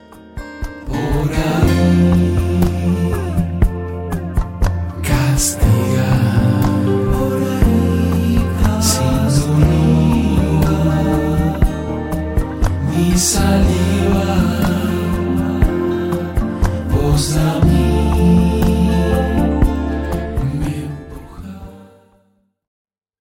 Grabado en casa.
Guitarras